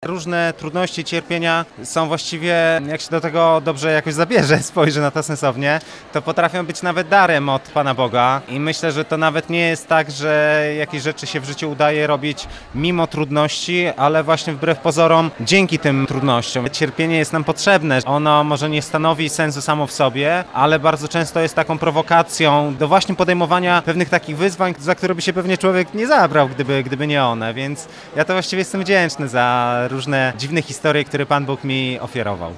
Janek Mela z wizytą na Katolickim Uniwersytecie Lubelskim